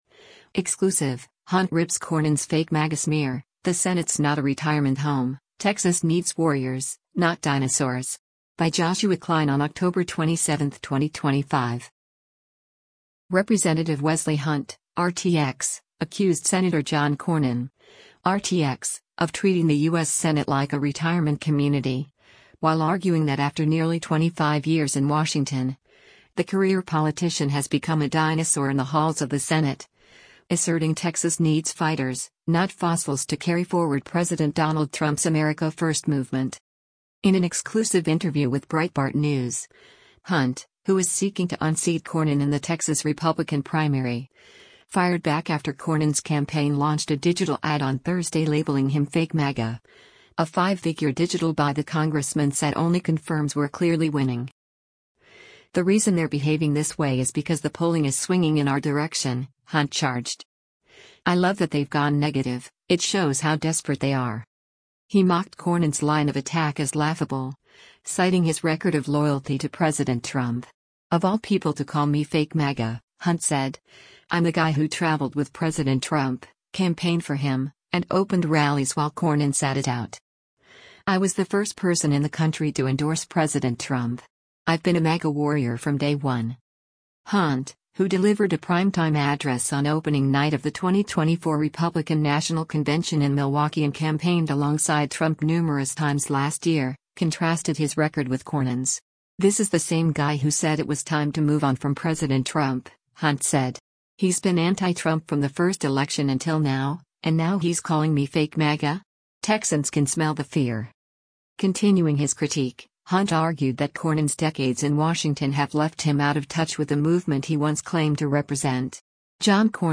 In an exclusive interview with Breitbart News, Hunt — who is seeking to unseat Cornyn in the Texas Republican primary — fired back after Cornyn’s campaign launched a digital ad on Thursday labeling him “fake MAGA,” a five-figure digital buy the congressman said only confirms “we’re clearly winning.”